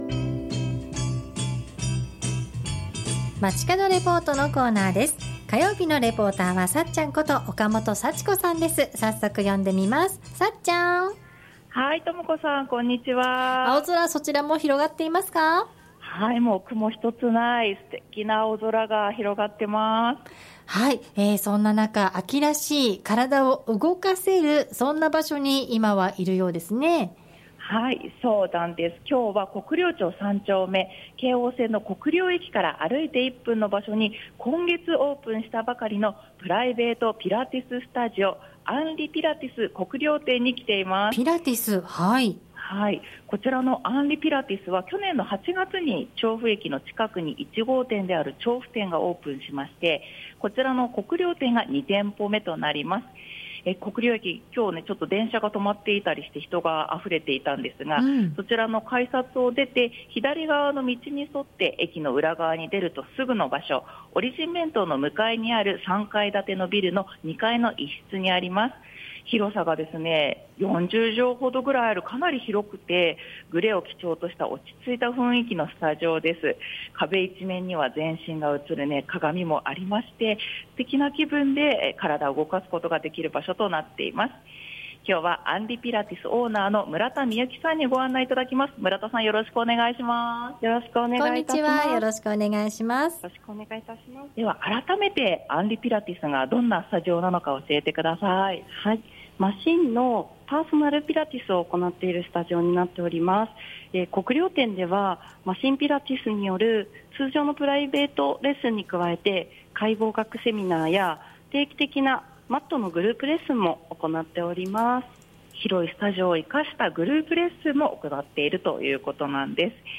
午後のカフェテラス 街角レポート
中継はスポーツの秋にぴったりの場所からお伝えしました。 お邪魔したのは京王線・国領駅から徒歩1分の場所に今月オープンしたばかりのプライベートピラティススタジオ「unri pilates 国領店」です。